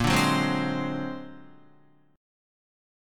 Bb+9 chord